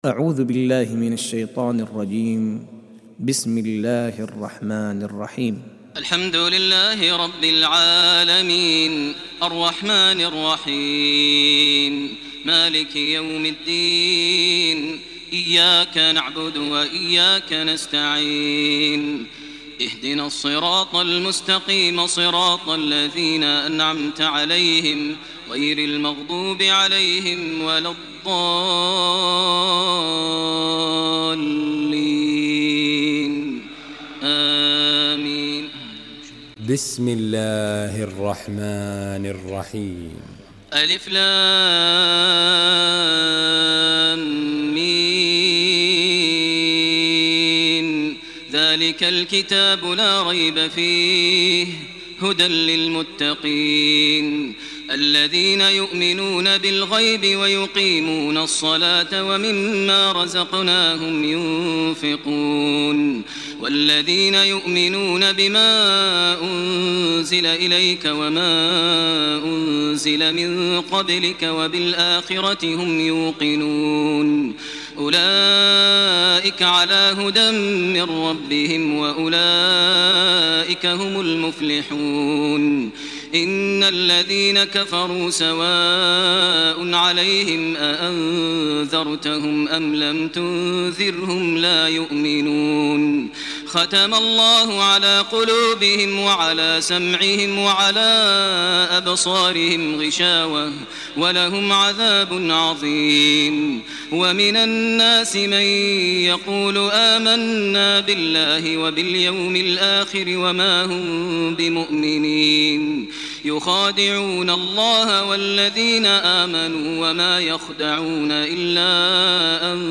تراويح الليلة الأولى رمضان 1428هـ من سورة البقرة (1-74) Taraweeh 1st night Ramadan 1428 H from Surah Al-Baqara > تراويح الحرم المكي عام 1428 🕋 > التراويح - تلاوات الحرمين